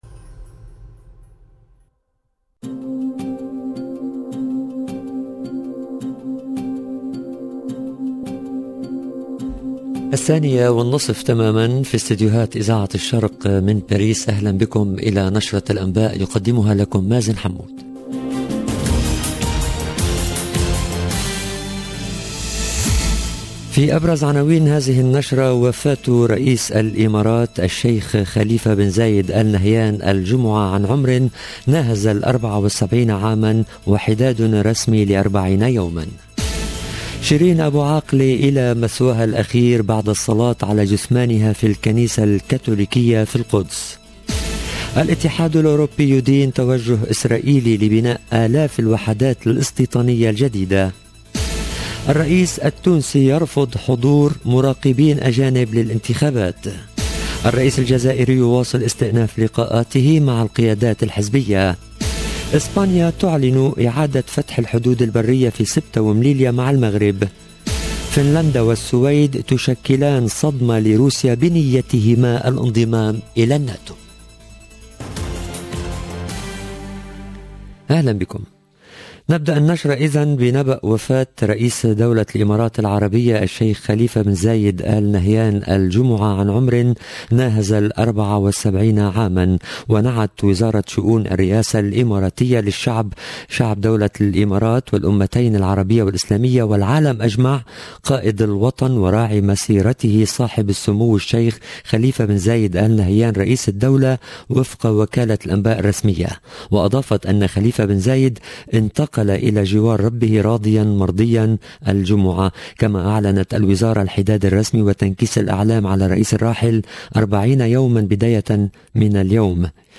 LE JOURNAL DE LA MI-JOURNEE EN LANGUE ARABE DU 13/05/22